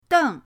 deng4.mp3